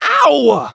hotshot_hurt_01.wav